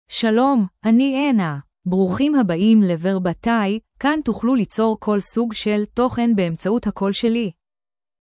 FemaleHebrew (Israel)
AnnaFemale Hebrew AI voice
Voice sample
Female
Anna delivers clear pronunciation with authentic Israel Hebrew intonation, making your content sound professionally produced.